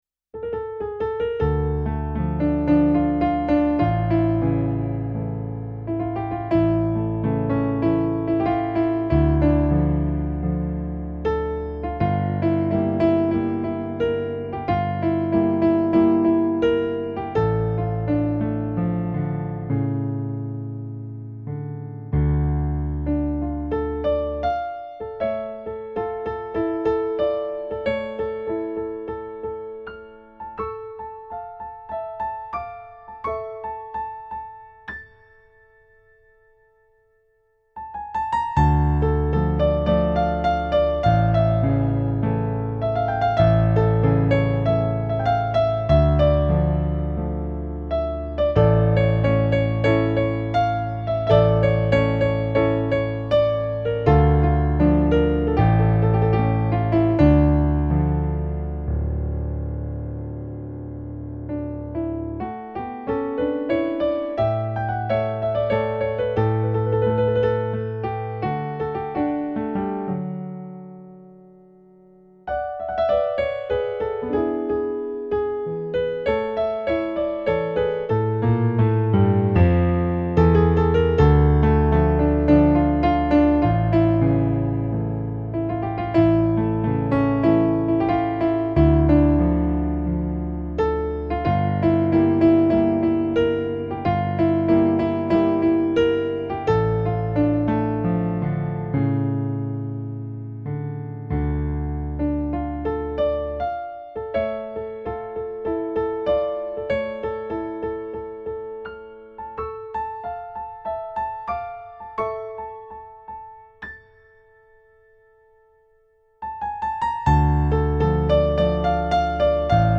Latin remix